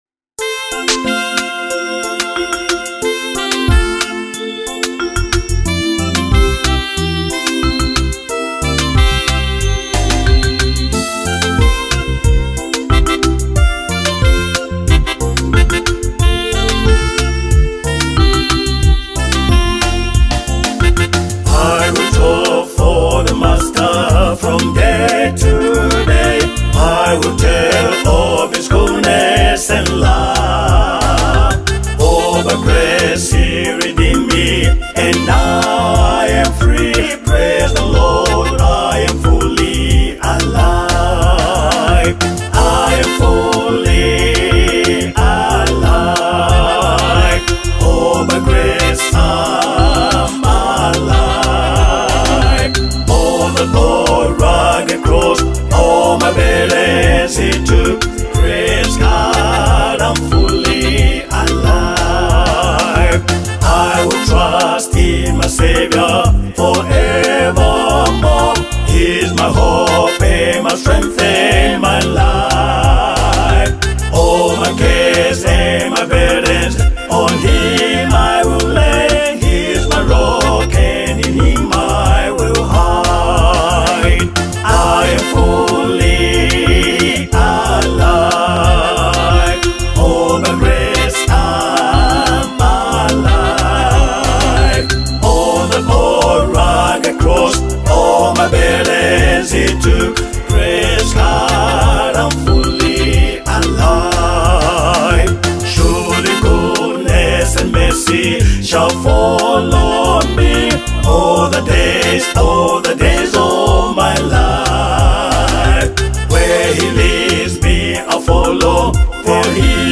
A Spirit-Filled Gospel Anthem
powerful vocals